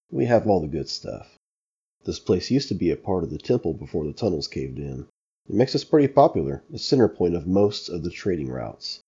Average Male